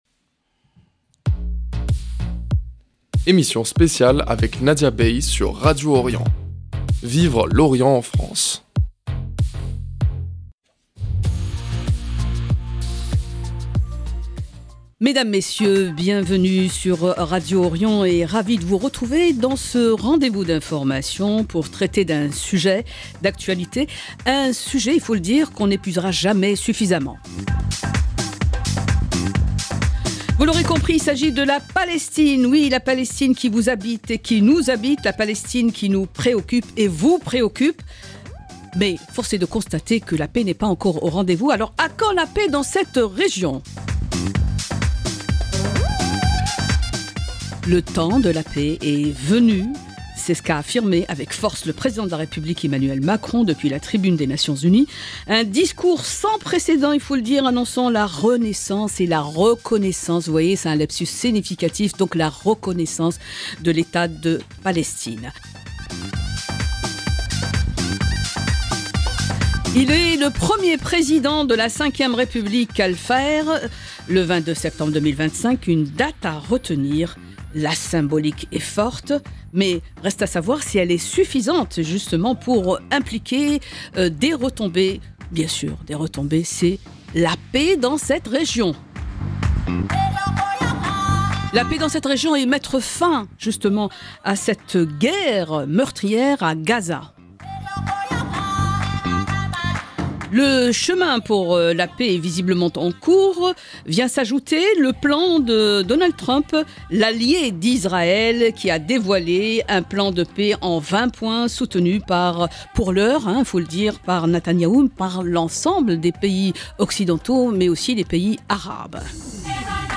Emission spéciale Analyse des réactions politiques et sociétales après le discours d’Emmanuel Macron sur la reconnaissance de l’État de Palestine.